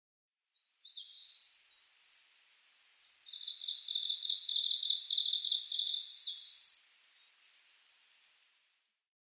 メボソムシクイ　Phylloscopus borealisウグイス科
日光市稲荷川上流　alt=1160m  HiFi --------------
Rec.: MARANTZ PMD670
Mic.: audio-technica AT825
他の自然音：　 エゾムシクイ・センダイムシクイ・カラ類